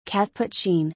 /ˌkætpʊˈtʃiːn/
pronunciation.mp3